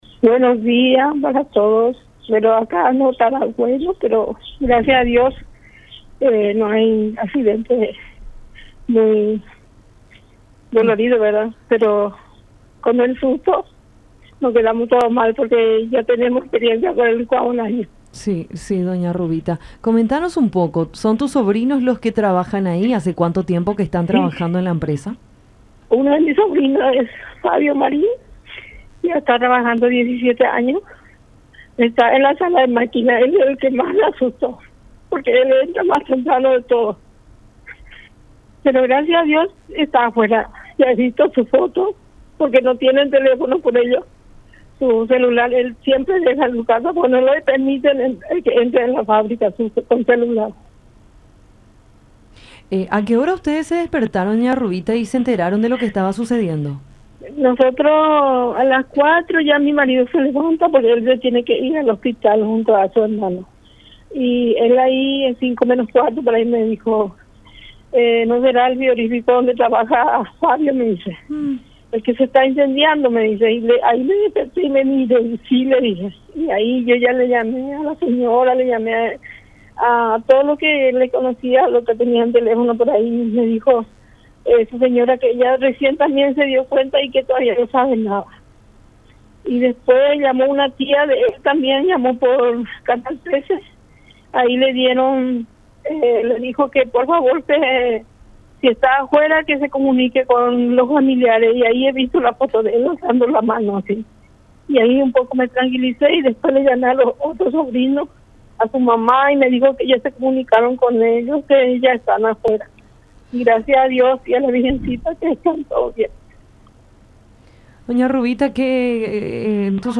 entre lágrimas
en diálogo con Enfoque 800 por La Unión